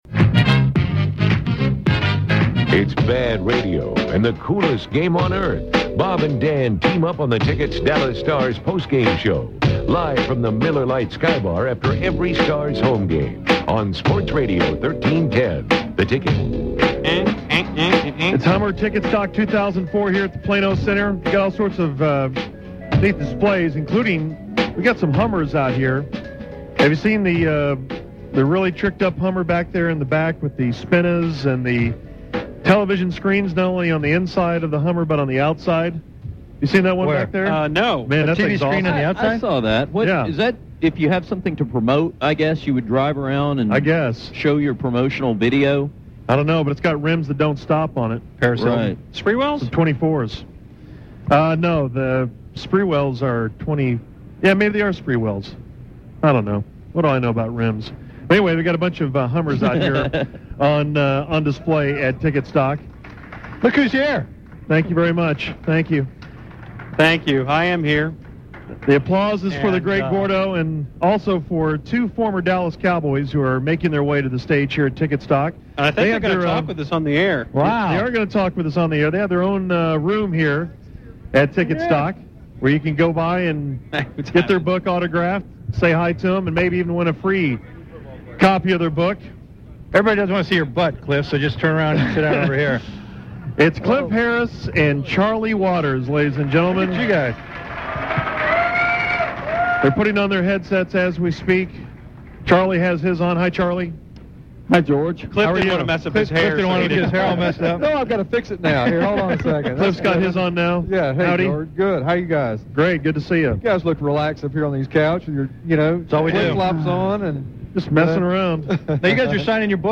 interview the great Charlie Waters and Cliff Harris of the Dallas Cowboys.